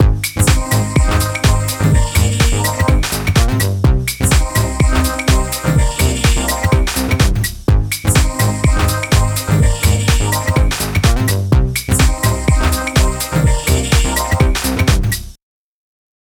I simply increased the amount of bands used, to produce a more refined, less grainy effect and also opened up the filters to ensure a nice bright sound was created.
The raw vocoded sequence with other parts.